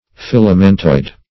filamentoid - definition of filamentoid - synonyms, pronunciation, spelling from Free Dictionary
Filamentoid \Fil"a*men*toid`\, a.